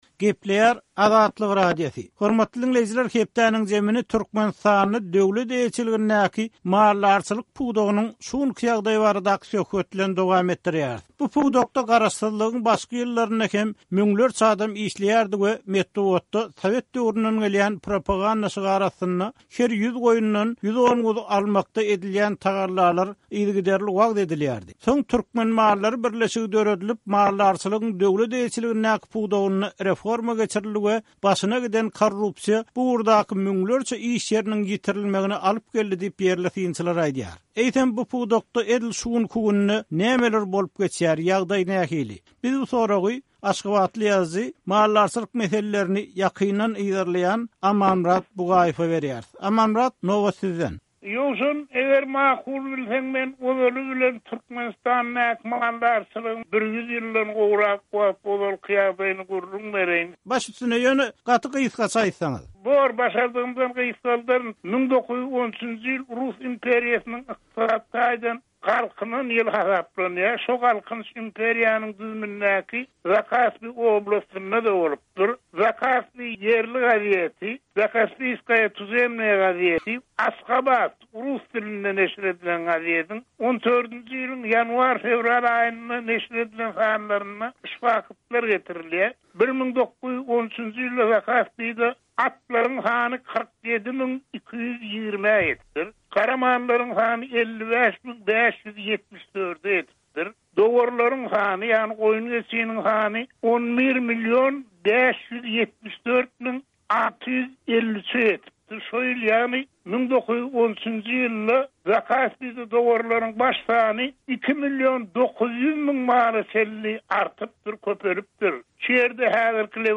söhbetdeşlik